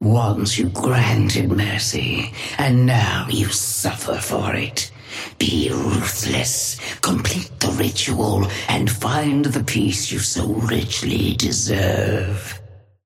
Sapphire Flame voice line - Once you granted mercy, and now you suffer for it.
Patron_female_ally_orion_start_05.mp3